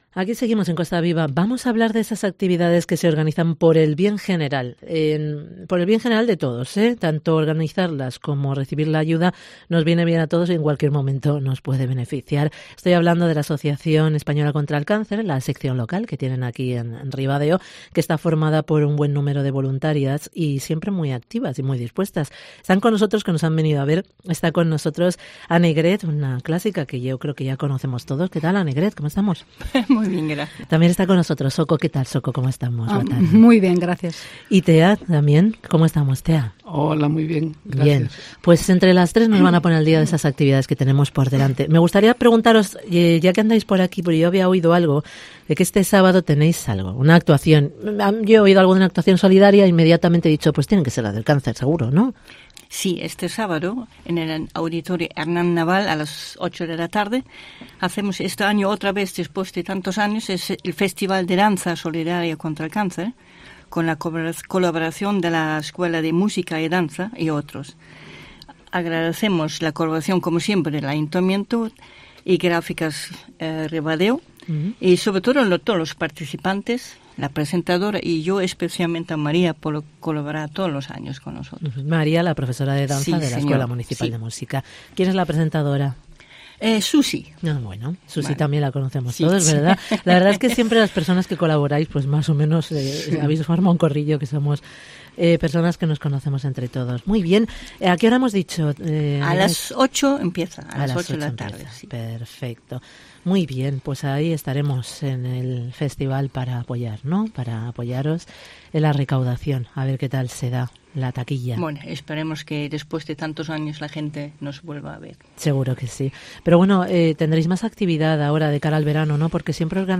Entrevista con las voluntarias de la AECC de Ribadeo